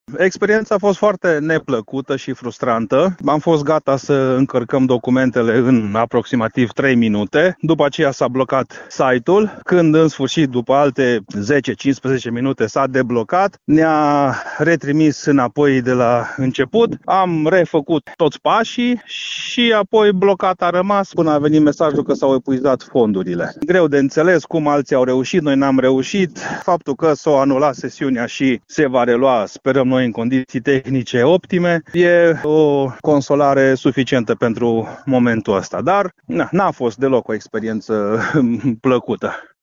un fermier din Timiș, a vorbit pentru Radio Timișoara despre blocajul apărut în timpul depunerii cererii de finanțare.